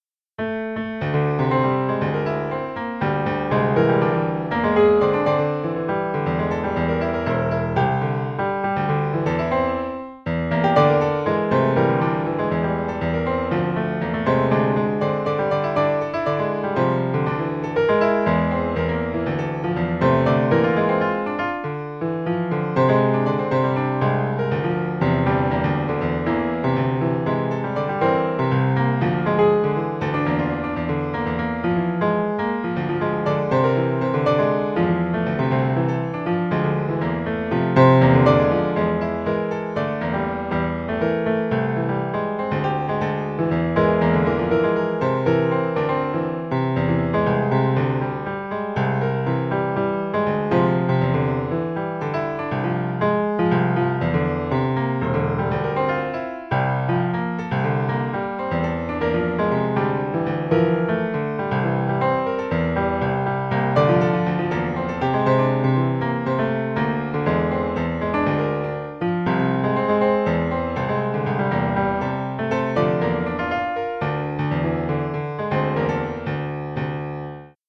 In the example, we use this value for octave transposition within our MIDI note-on message.
TSV_MIDImessage_finalB_edited.mp3